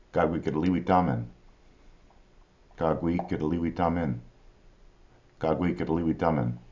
kag-wi  k-deli-wi-ta-men